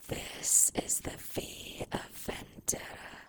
speech-female_hpsModel_stochastic
female model residual sinusoids sms-tools speak stochastic talk sound effect free sound royalty free Memes